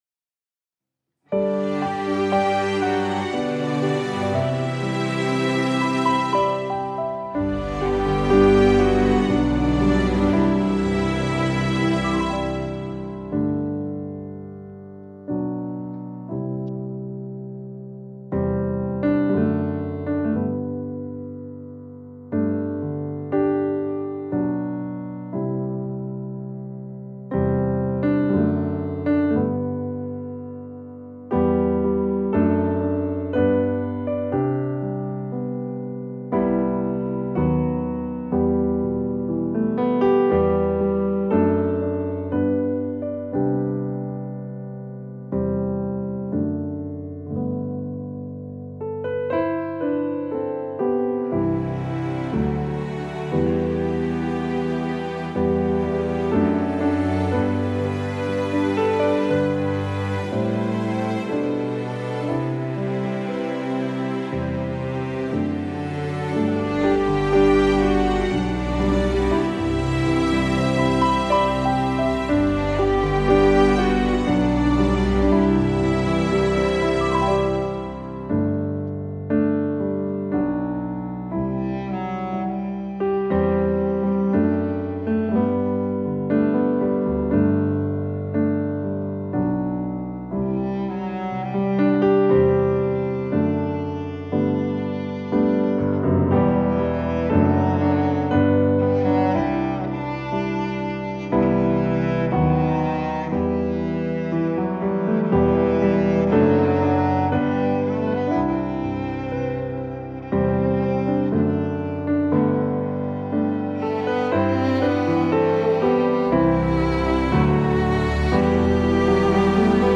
Мінусовка